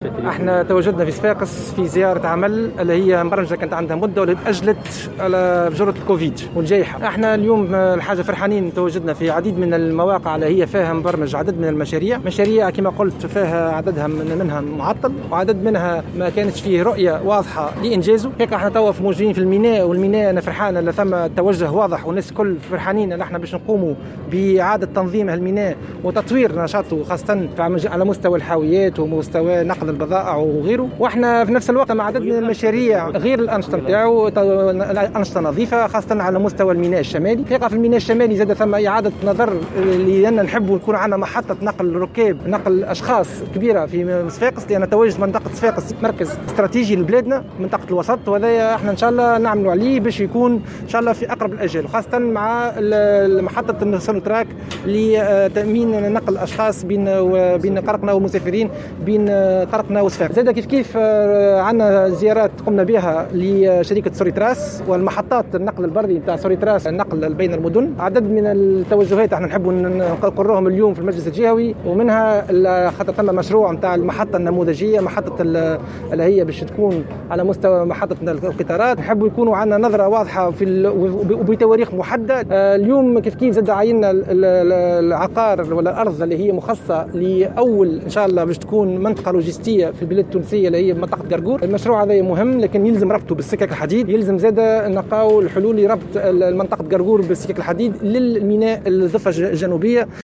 وفي تصريح لمراسلنا بالجهة ، أفاد الوزير بأنه قام بعدة زيارات إلى المنطقة اللوجستية بقرقور ومحطة النقل البري بصفاقس والميناء التجاري ومحطة القطار.